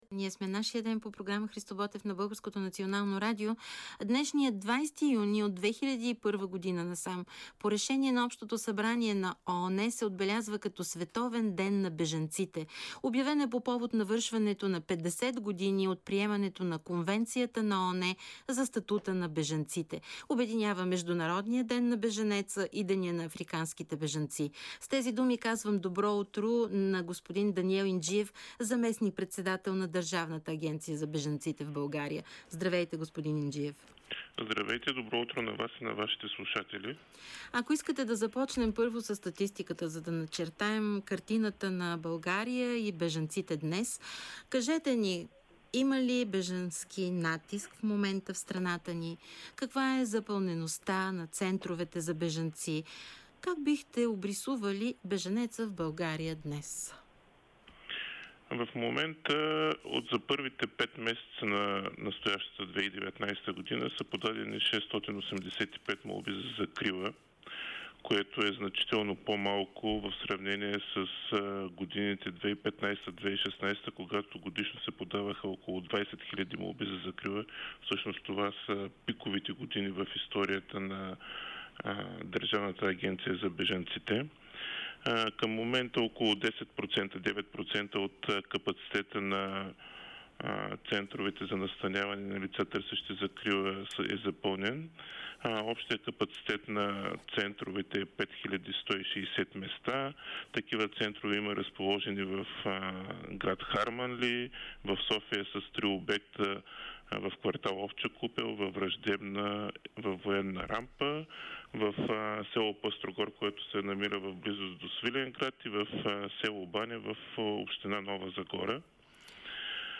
Даниел Инджиев, заместник-председател на ДАБ при МС за програма „Христо Ботев“ – БНР Звуковият файл е предоставен от съответната програма на БНР